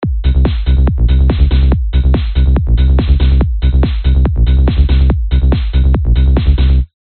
描述：这里有一些类似果阿的实验性声音。请自由使用它。
Tag: 贝斯 电子 电子 果阿 psytrance techno